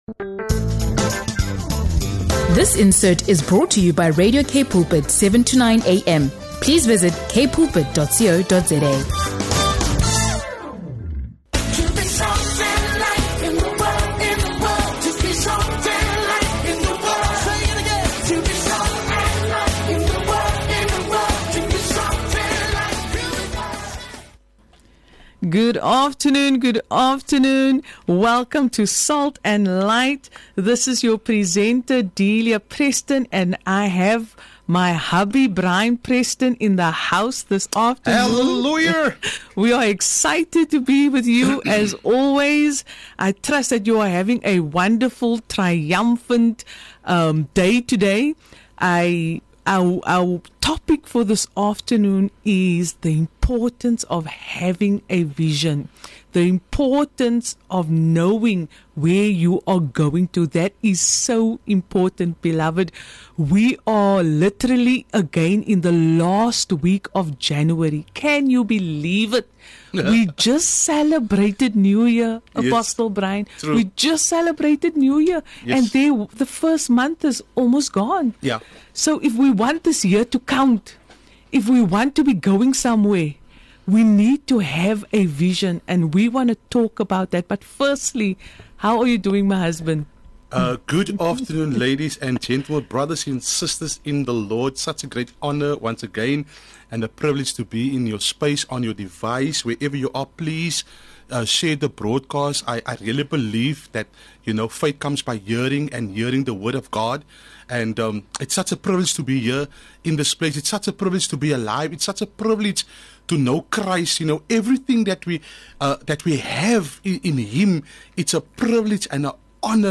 in the studio for a powerful, guest-free conversation on the importance of vision